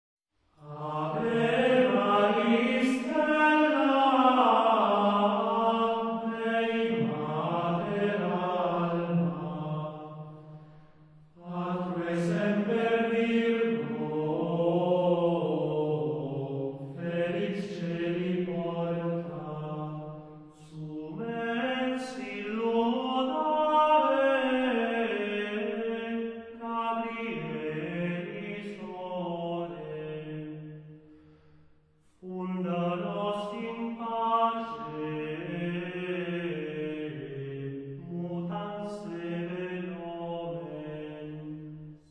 Григорианские песнопения во славу Пресвятой Девы Марии.
Запись сделана в храме Сан-Лоренцо ин Мондинари (Италия) в апреле 1996 г.